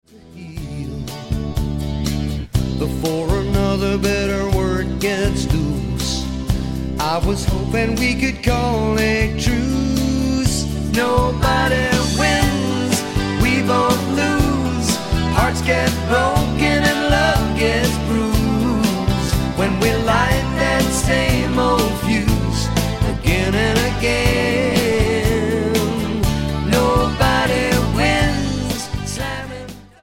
Genre / Stil: Country & Folk